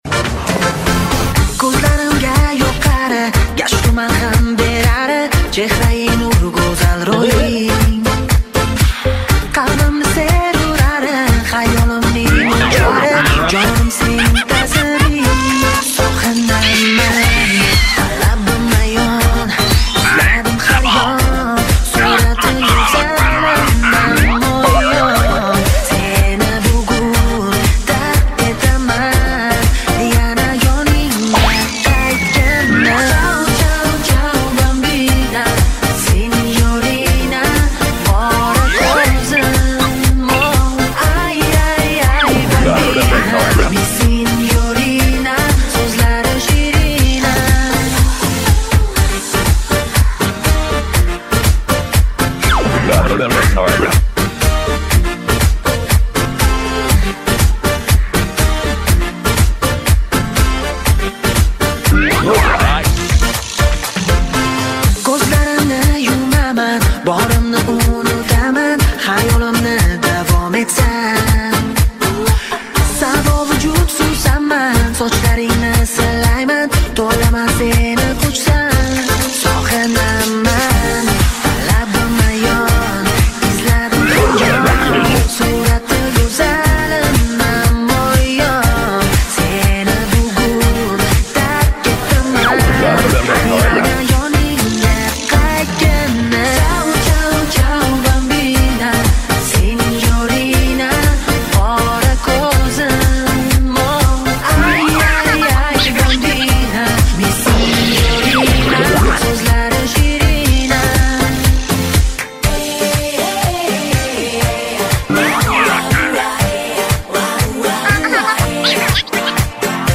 • Жанр: Узбекская музыка